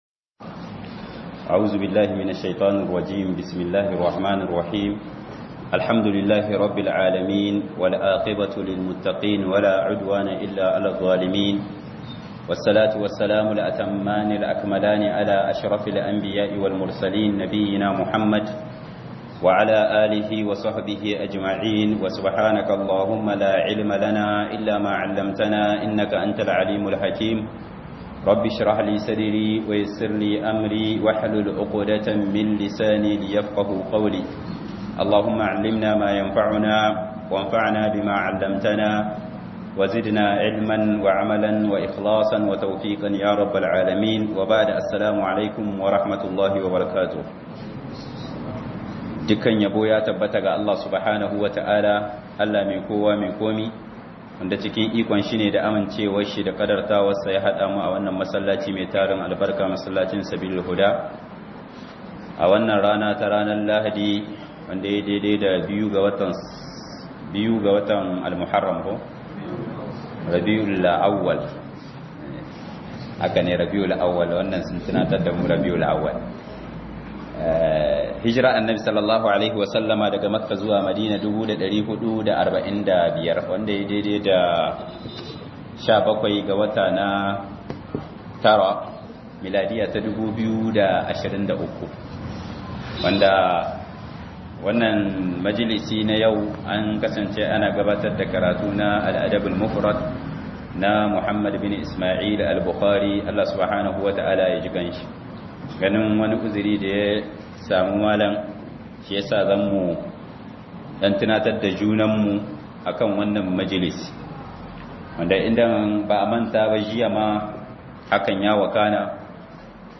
ILIMI DA MALAMAI DA LADUBAN ILIMI 2023-09-17_18'48'53 - MUHADARA